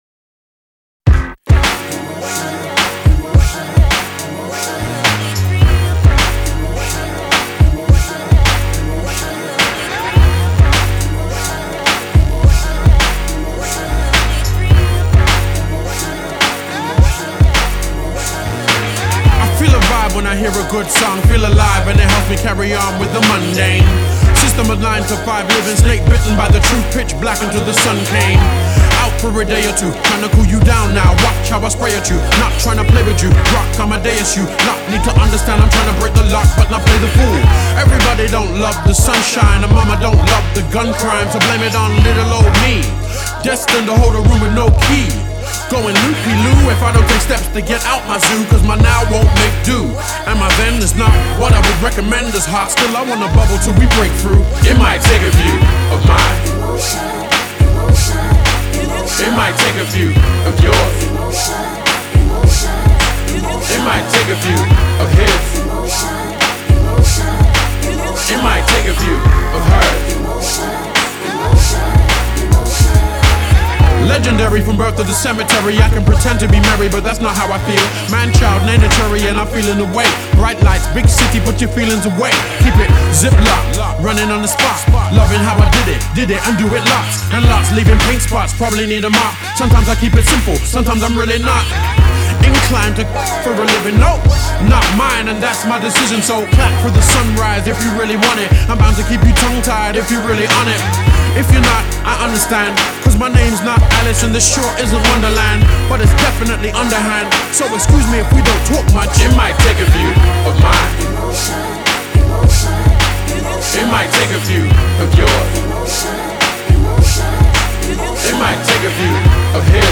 great sound and rhymes on this one…straight from London UK